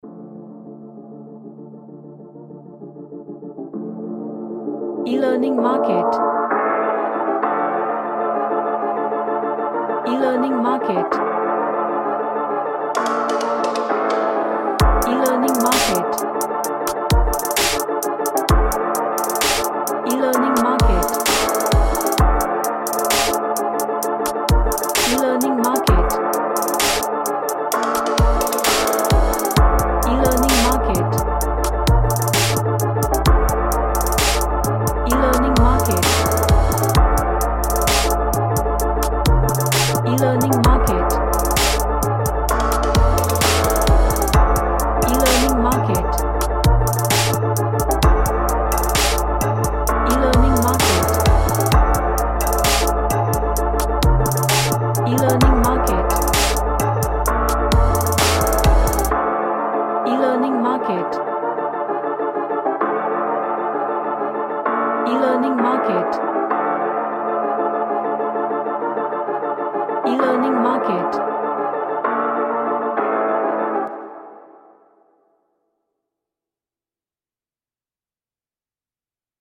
A synthesized dark track
Dark / Somber